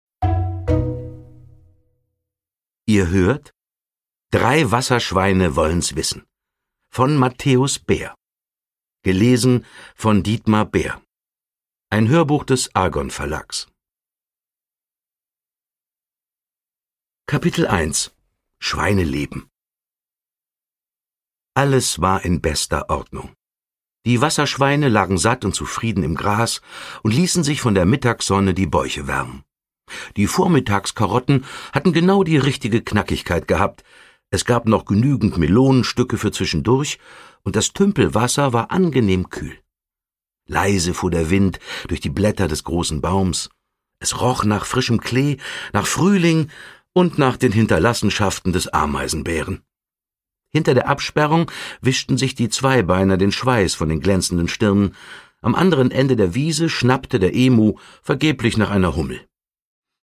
Produkttyp: Hörbuch-Download
Gelesen von: Dietmar Bär
Dietmar Bär nimmt es bei den Wasserschweinen stimmlich mit einem ganzen Zoo auf!